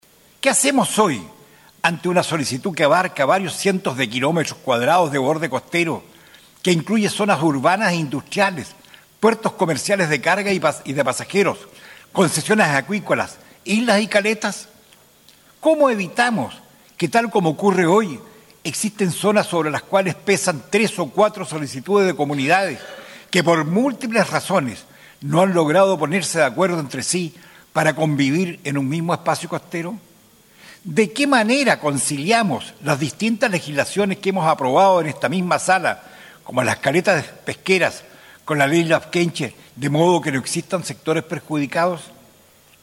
En el hemiciclo de la cámara alta, el Senador Rabindranath Quinteros expuso lo complejo del tema, con intereses patrimoniales y productivos cruzados y con legislaciones que parecen contraponerse entre ellas.